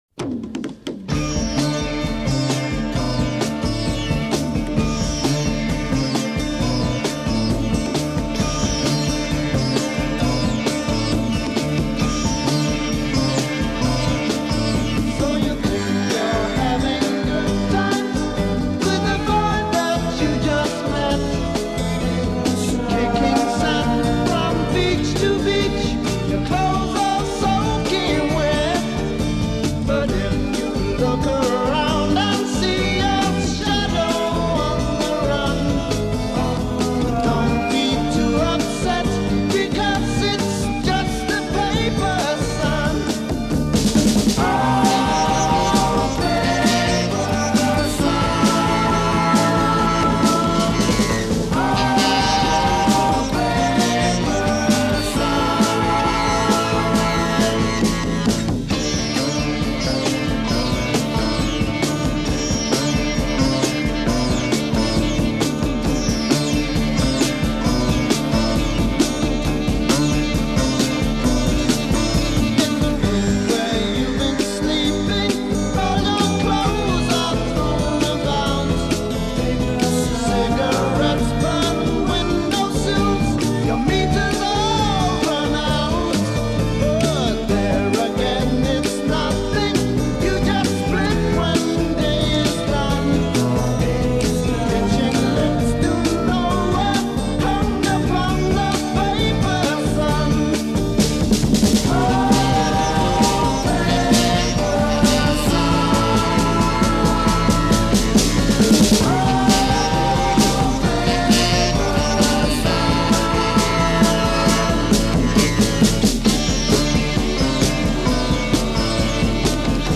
Recorded at Olympic Sound Studios, London
Intro 16 Drums, sitar, tambura, flute, multitracked
Refrain 16 Chorus over guitar/sitar runs b